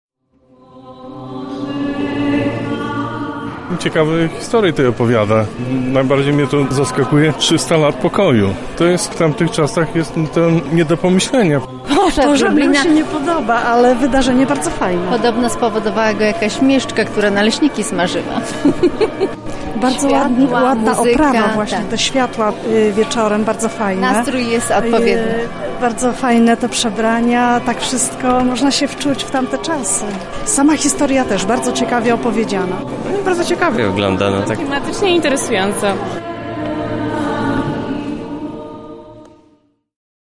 Wczoraj mieszkańcy, aby upamiętnić to wydarzenie jeszcze raz przeszli ulicami Starego Miasta.